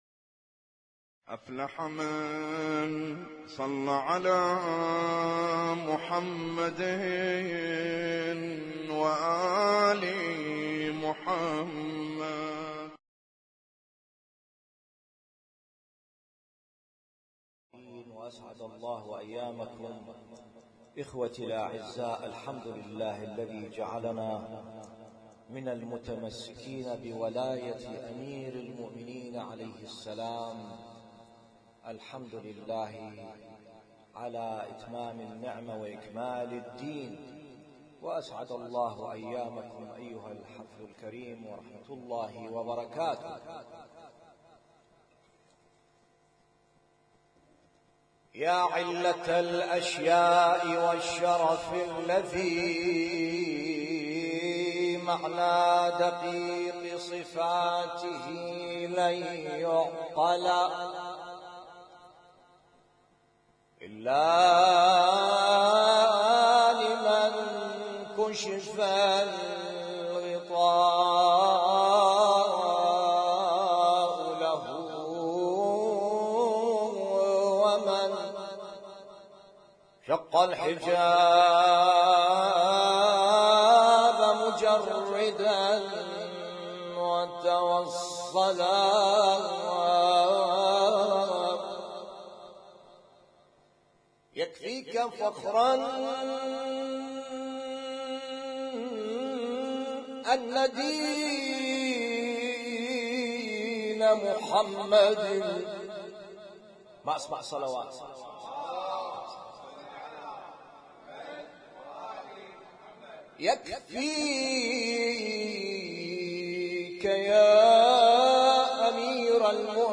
اسم التصنيف: المـكتبة الصــوتيه >> المواليد >> المواليد 1436